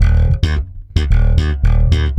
-AL DISCO.E.wav